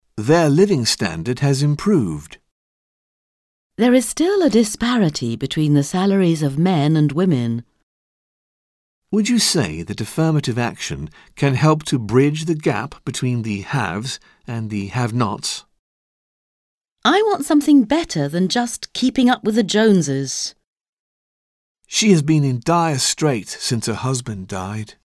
Un peu de conversation - Les composantes de la société